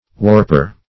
Search Result for " warper" : The Collaborative International Dictionary of English v.0.48: Warper \Warp"er\, n. 1. One who, or that which, warps or twists out of shape.